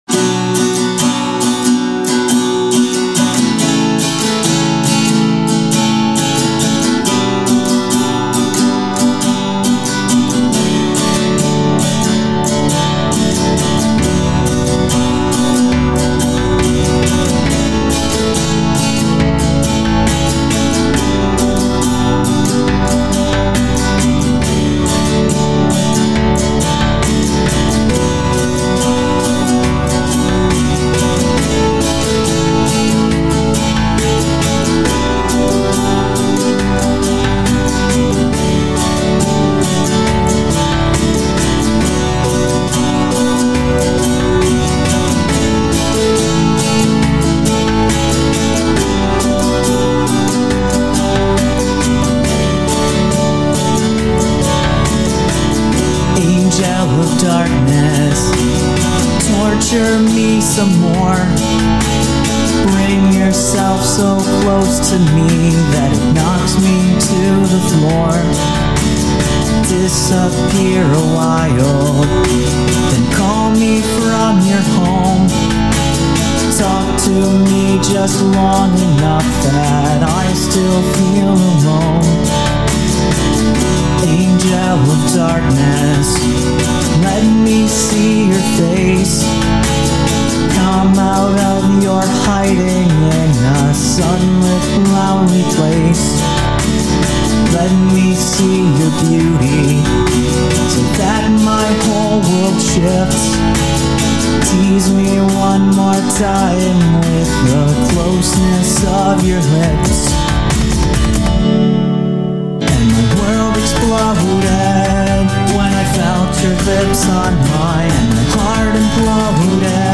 Lyrics, vocals, and guitar by me